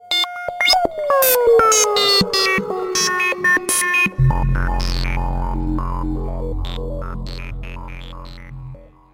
• Качество: 128, Stereo
короткие
робот
Непонятный звук, но на смс самы раз